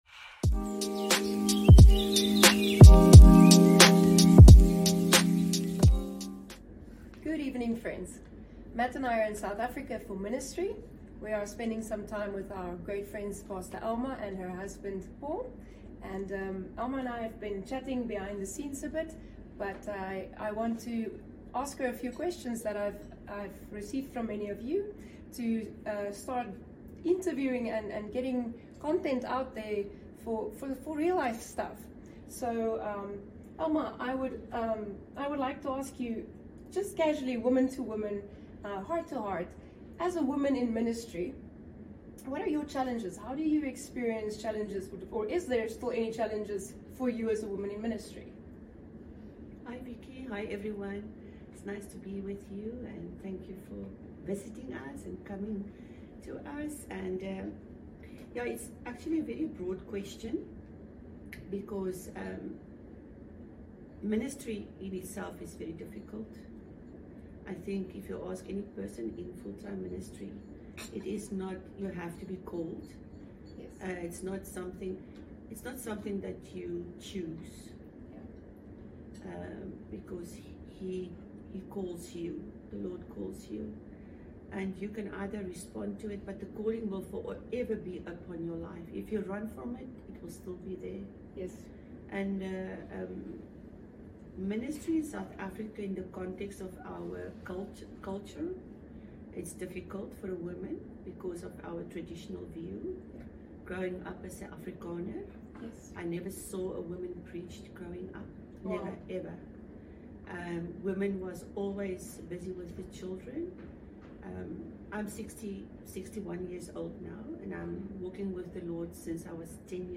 This honest 24-minute chat is full of encouragement, gentle wisdom, and reminders of God's presence in the midst of it all.